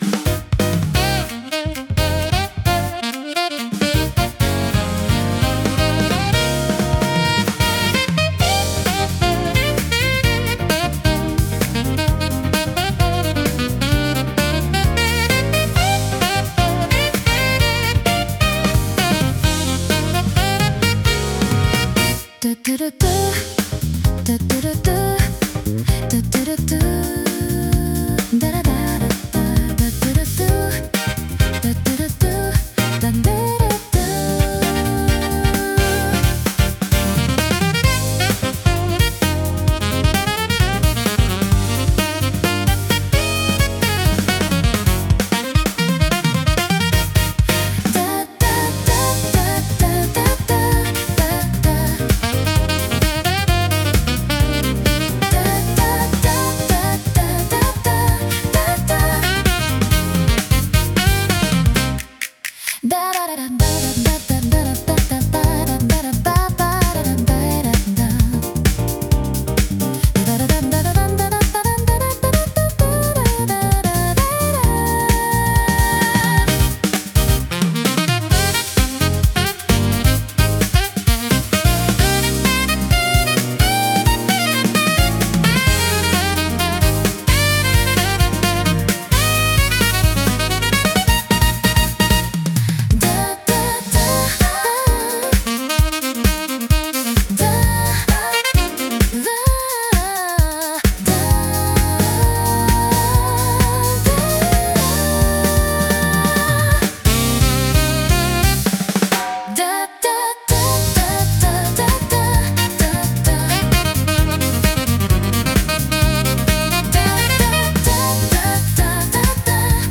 インスト , サックス , ジャズ , ドゥーワップ
イメージ：インスト,ジャズ,ドゥーワップ